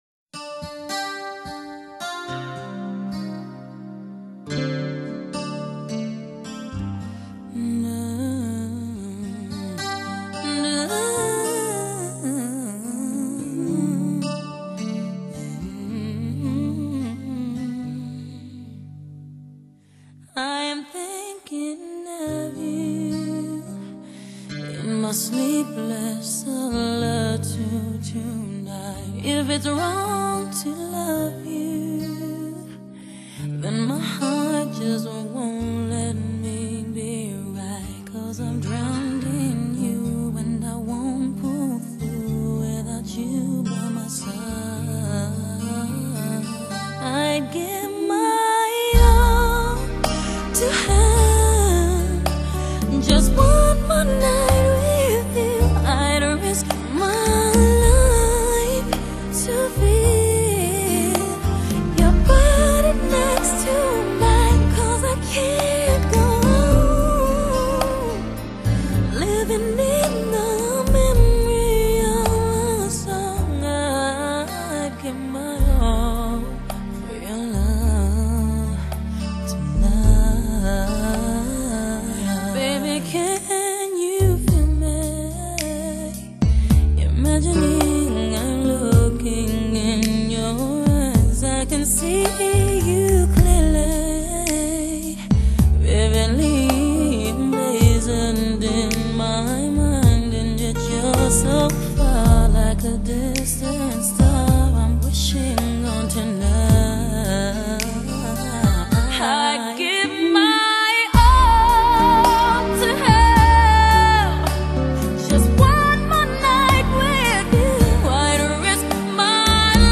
Genre: Pop, Soul